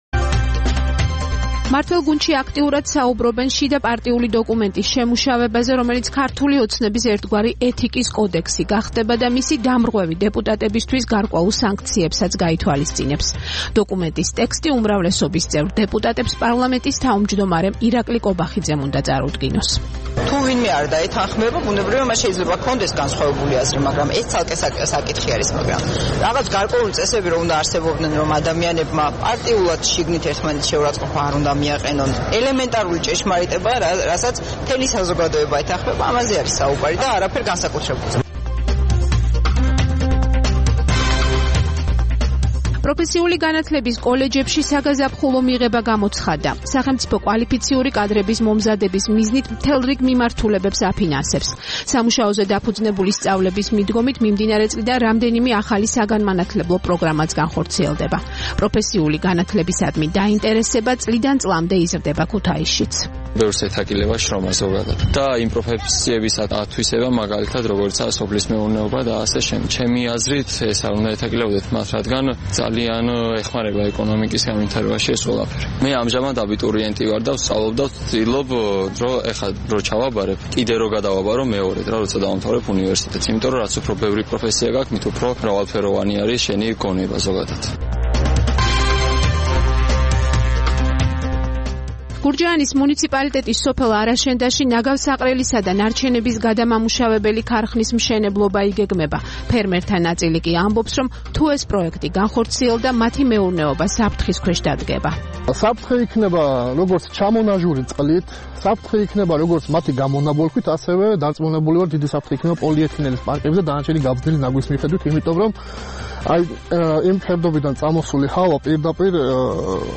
13 აპრილს რადიო თავისუფლების "დილის საუბრების" სტუმარი იყო